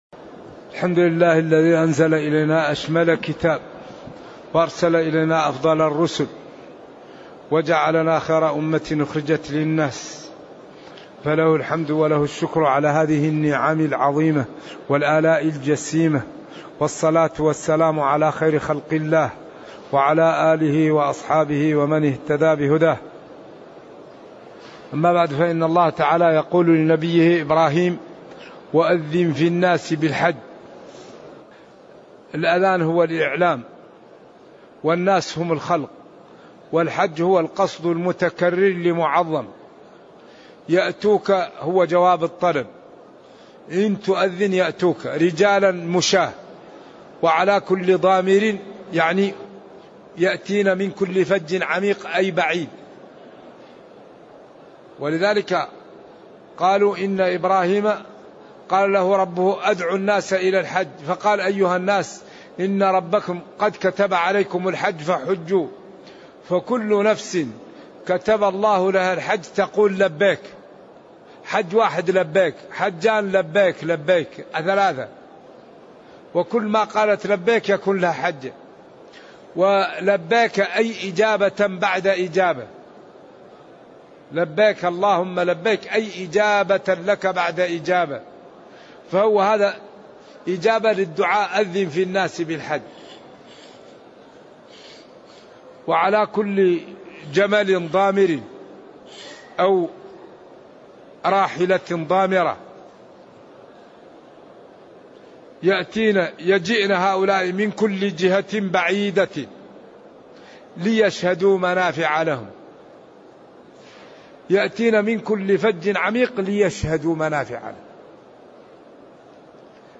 تاريخ النشر ٢ ذو الحجة ١٤٤١ هـ المكان: المسجد النبوي الشيخ